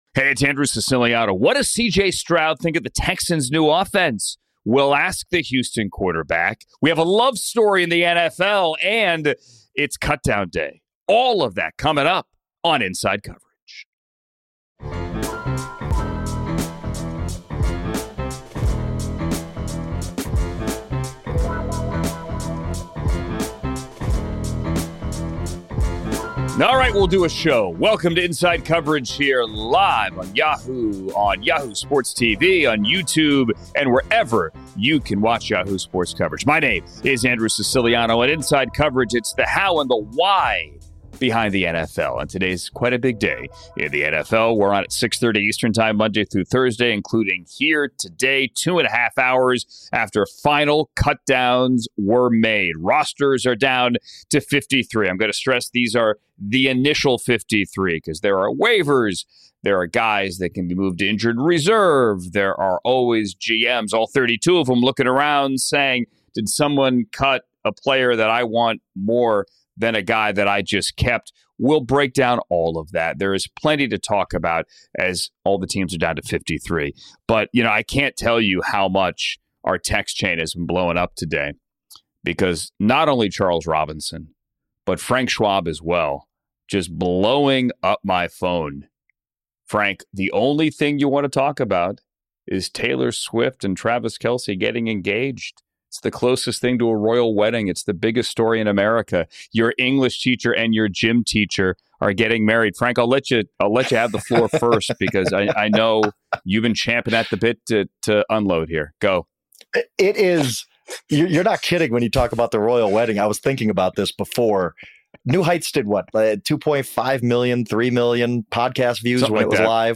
Plus, Houston Texans quarterback C.J. Stroud joins the show to talk about Year 3 in the league. And Travis Kelce and Taylor Swift are engaged!